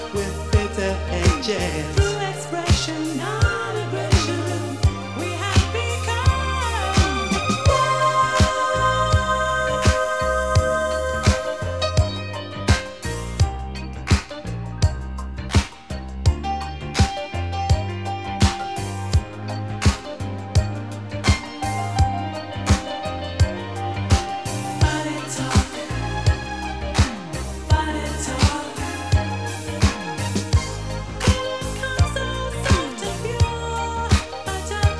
Жанр: R&B / Танцевальные / Электроника / Соул